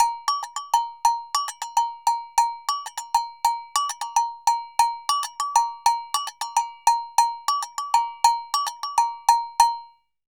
Agogo_Samba_100_1.wav